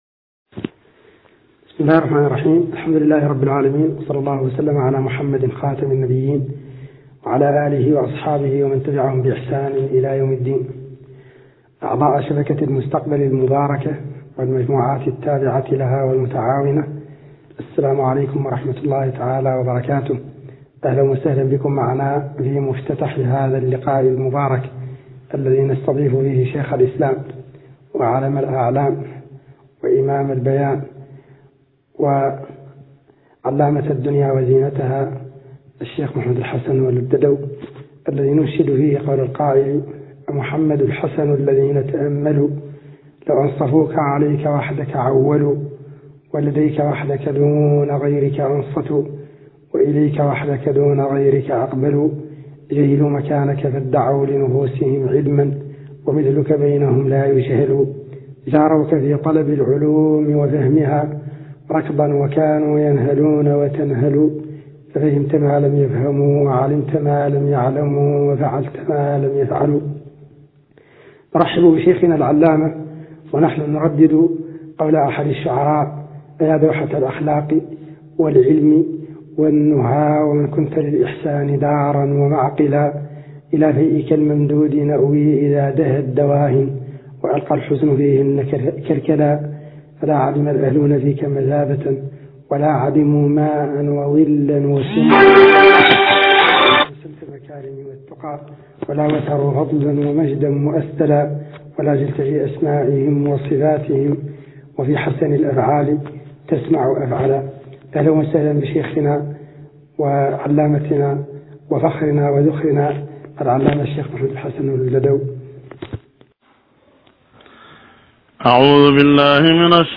فتاوى المسلم - حلقة الافتاء - الشيخ محمد الحسن ولد الددو الشنقيطي